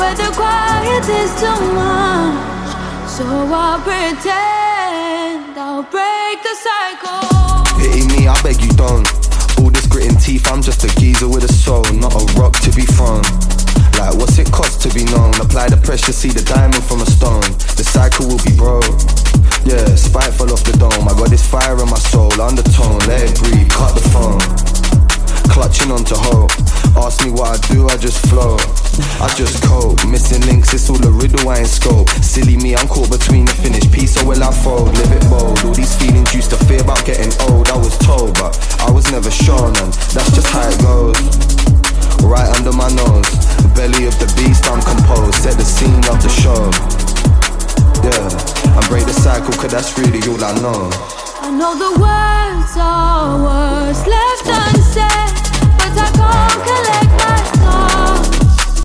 Drum&Bass